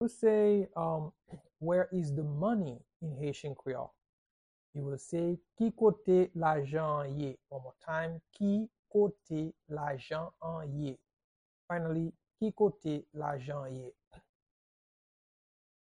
Pronunciation and Transcript:
How-to-say-Where-is-the-money-in-Haitian-Creole-–-Ki-kote-lajan-an-ye-pronunciation-by-a-Haitian-teacher.mp3